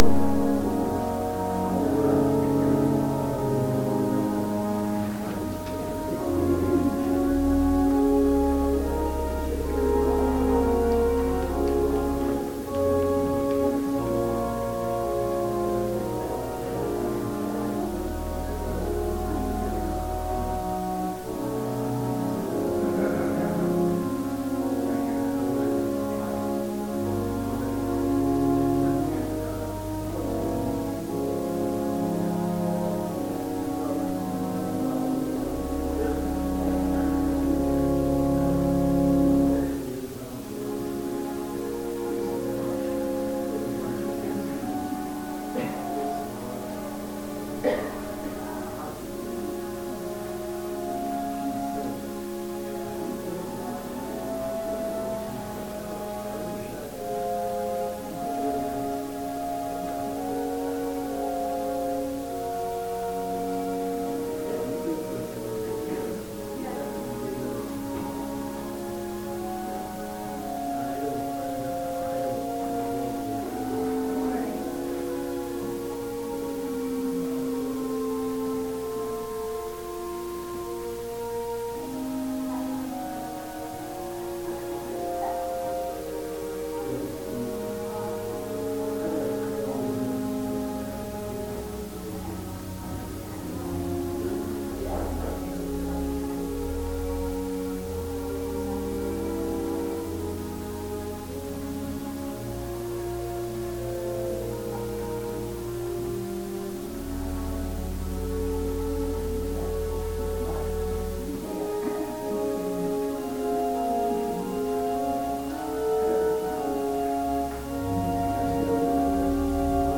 – St. Paul Lutheran Church